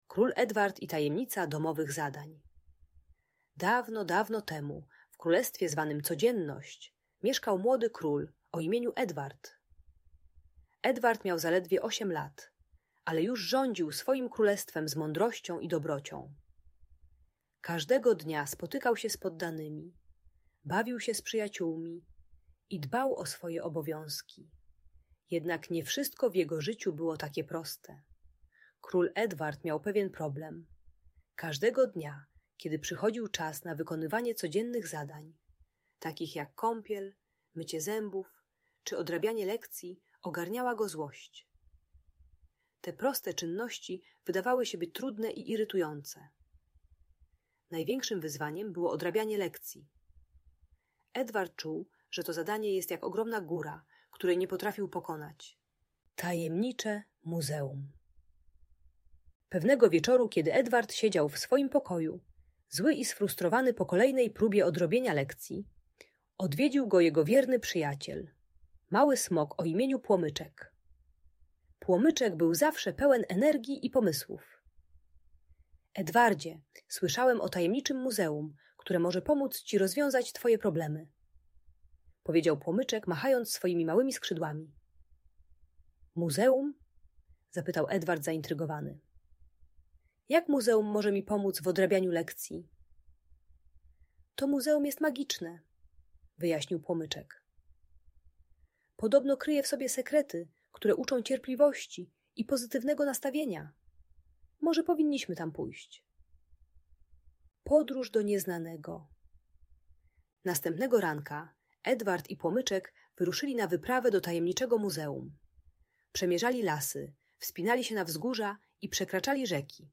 Król Edward i Tajemnica Domowych Zadań - Bunt i wybuchy złości | Audiobajka